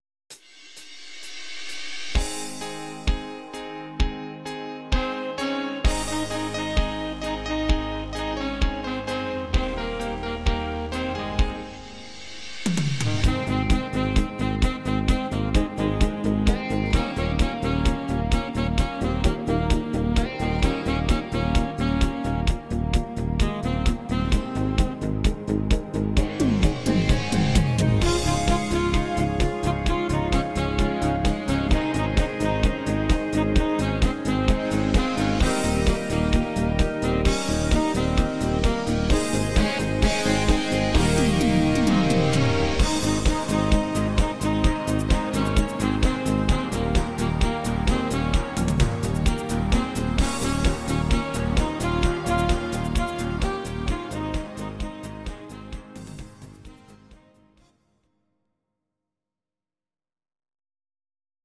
tiefere Tonart -4